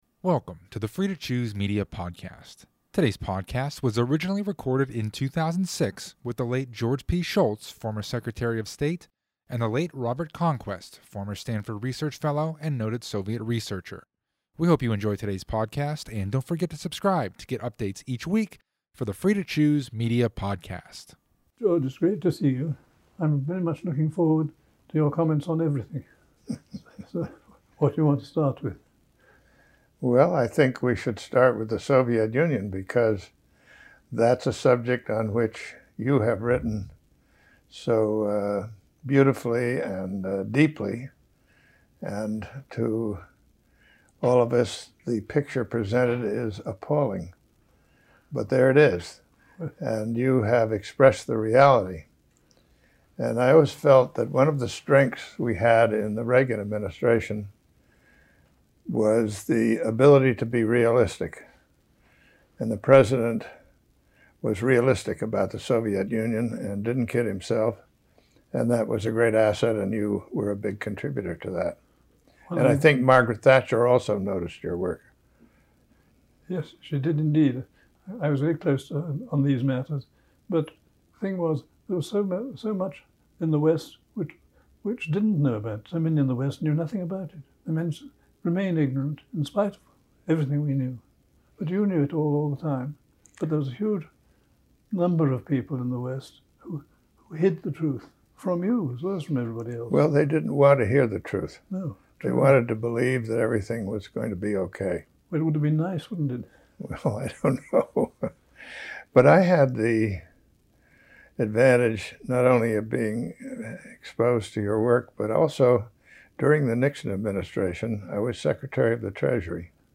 Episode 114 – A Conversation with George P. Shultz and Robert Conquest